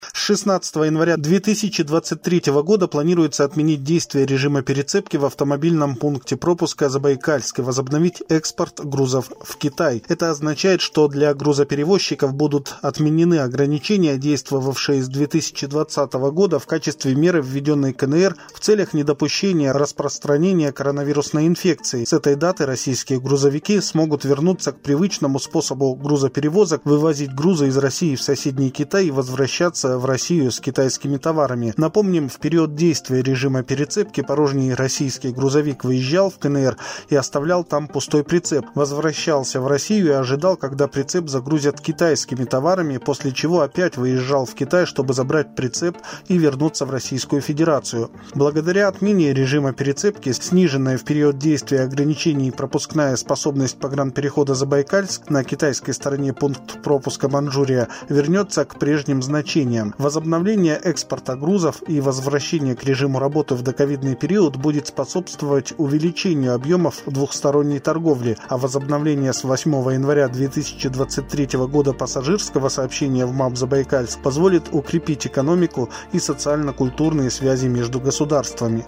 Диктор новости